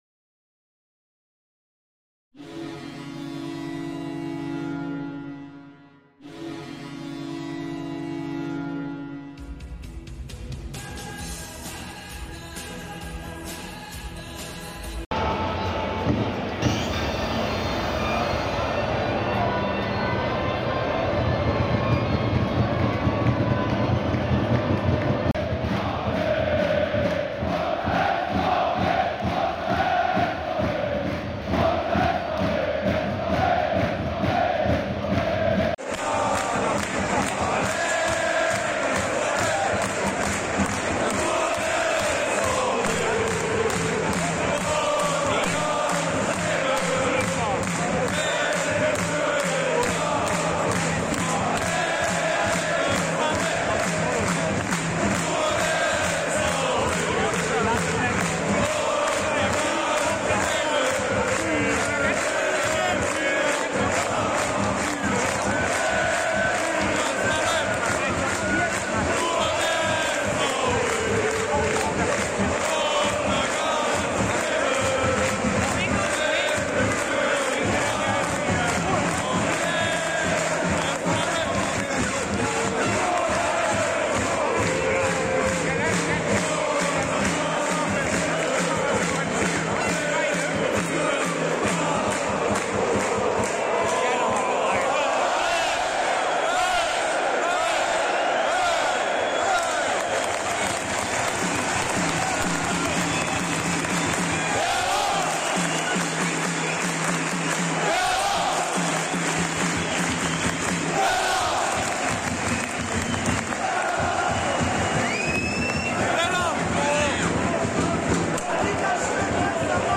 Werder Bremen vs. 1. FC Heidenheim - Impressionen aus dem Stadion
- IMPRESSIONEN AUS DEM STADION
werder-bremen-vs-1-fc-heidenheim-impressionen-aus-dem-stadion.mp3